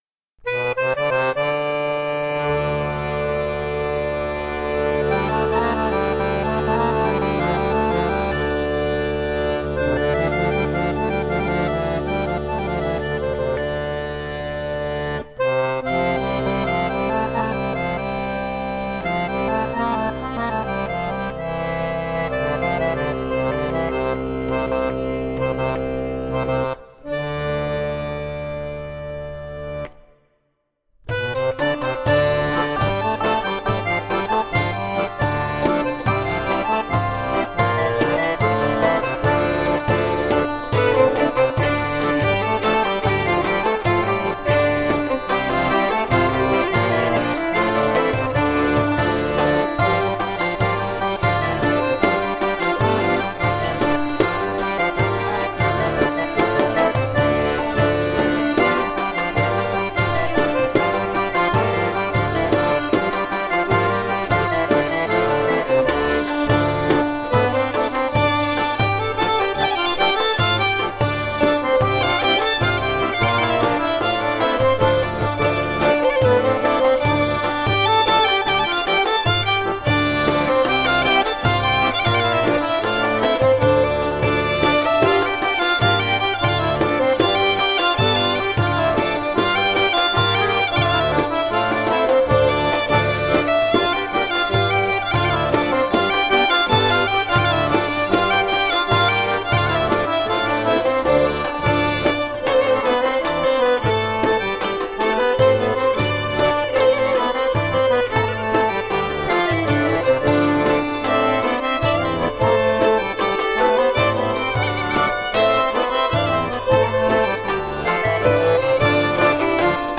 Eastern and western european folk music..